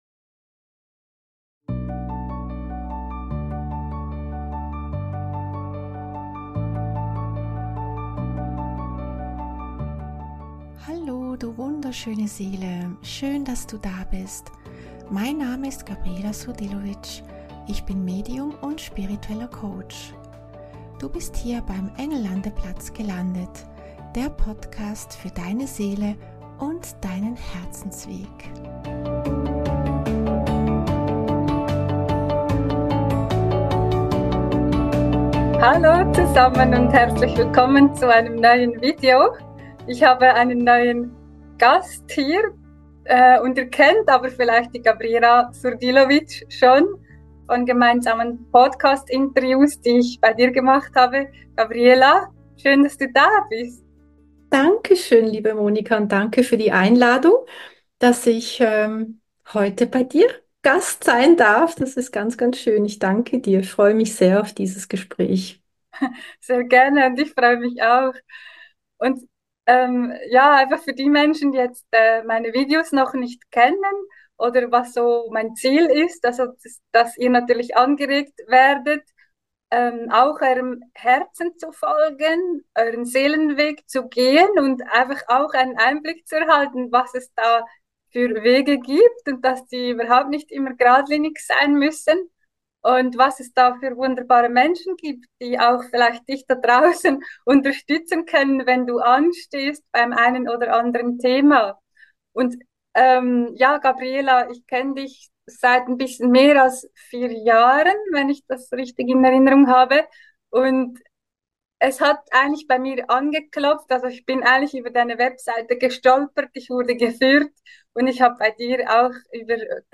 Das Gespräch ist etwas länger her, doch ist es sehr spannend da reinzuhören. Wir sprechen über das Thema Spiritualität und wie sich die Seele weiterentwickeln kann.